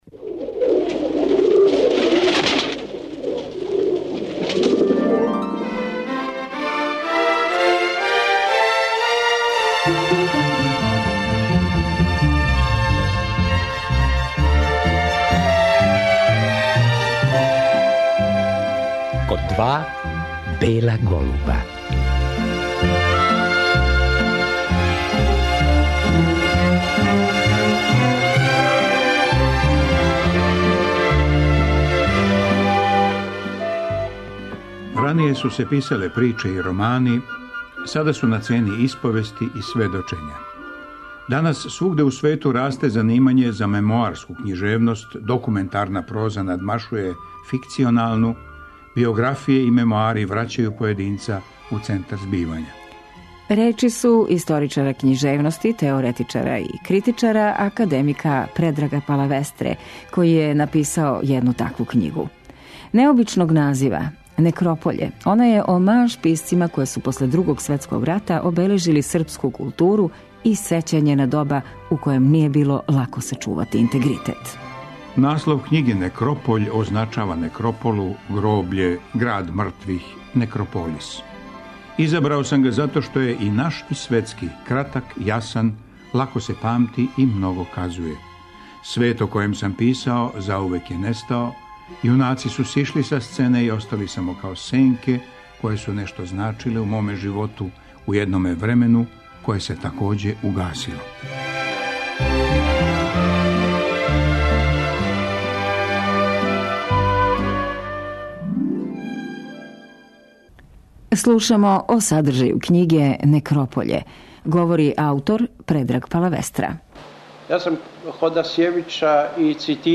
На вест да је преминуо Предраг Палавестра, историчар и теоретичар модерне српске и југословенске књижевности, емитујемо разговор са академиком који је вођен 2004. године.